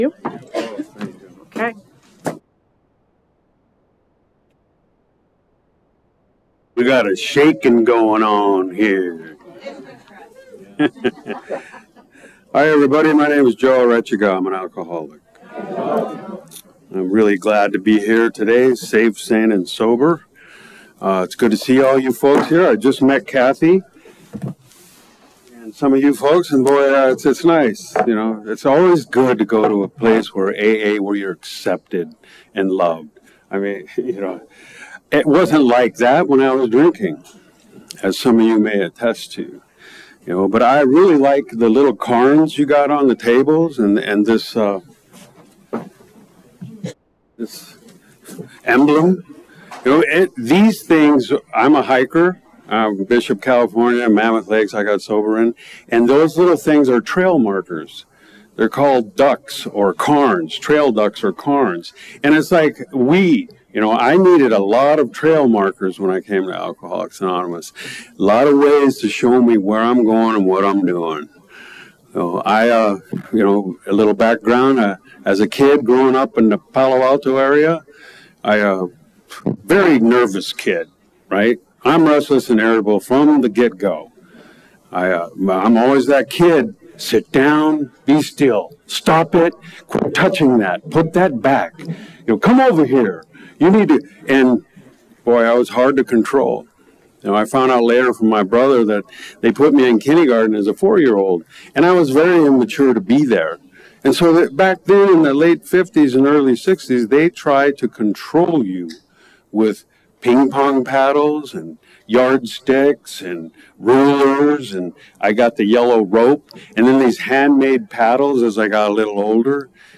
33rd Indian Wells Valley AA Roundup with Al-Anon and NA